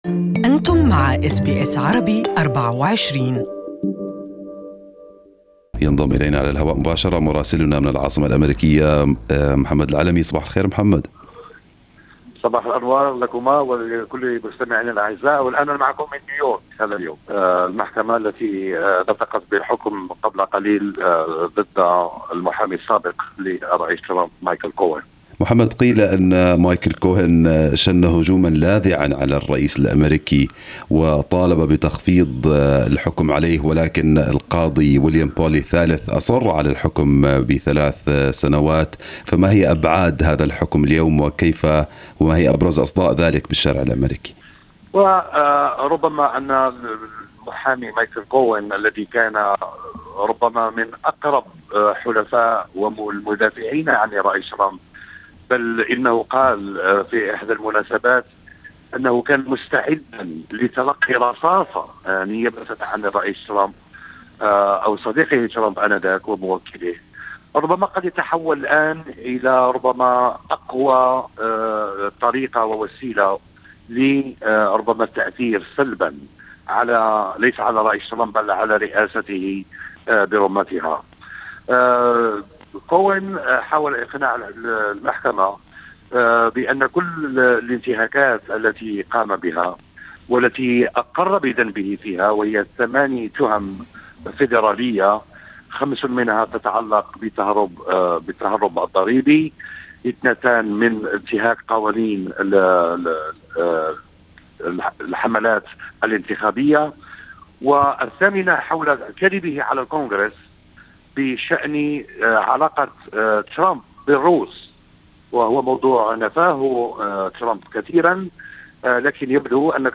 Our correspondent has the details from New York